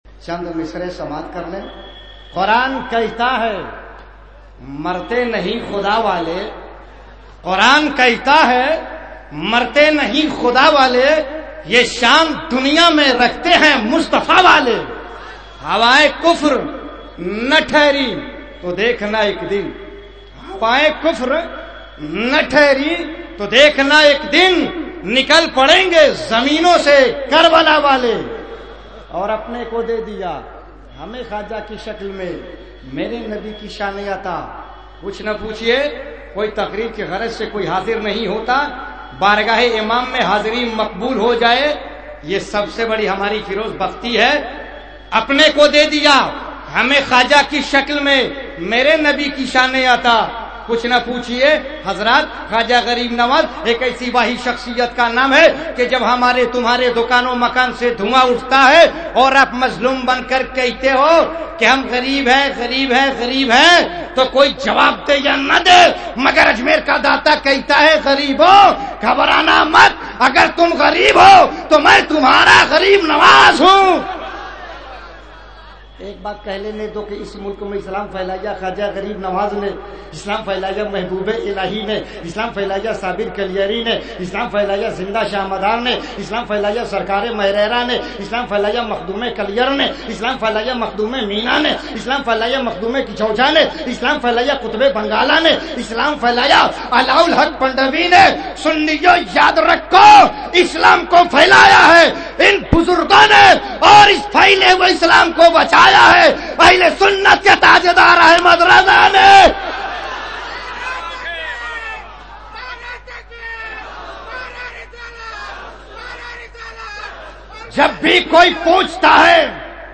011-Speech.mp3